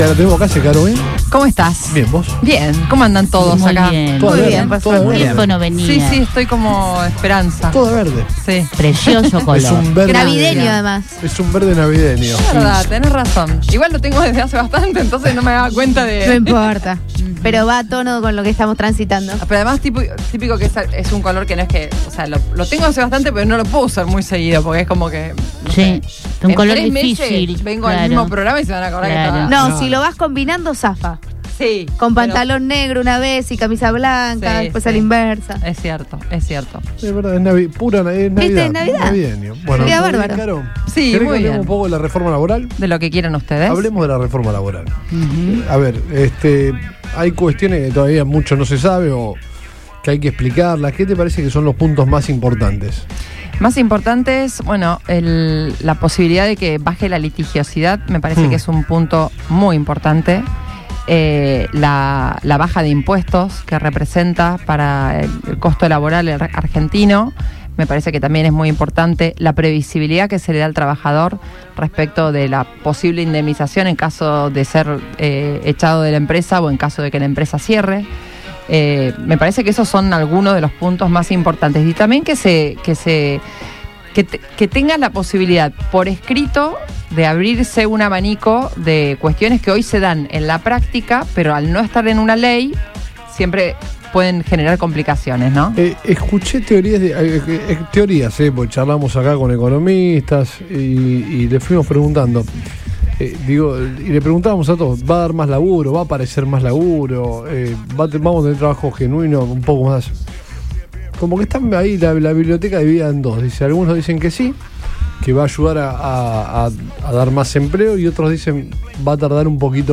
La senadora nacional por Santa Fe, Carolina Losada, visitó los estudios de Radio Boing y dialogó con el equipo de Todo Pasa – Turno Tarde sobre el anteproyecto de reforma laboral impulsado por el Gobierno nacional, que será tratado próximamente en el Congreso.